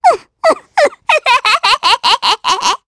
Pansirone-Vox_Happy2_jp.wav